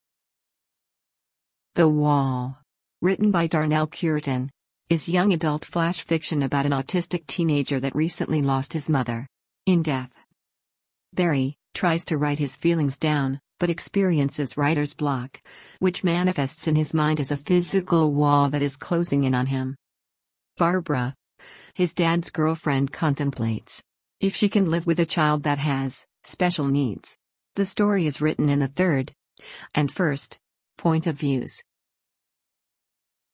I created an Artificial Intelligent Voice (Text To Audio Software) to read the above synopsis about “The Wall.”
the-wall-synopsis.mp3